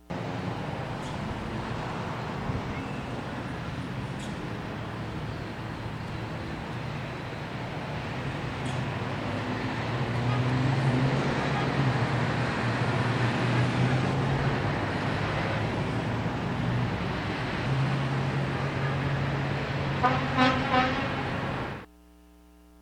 Outside.wav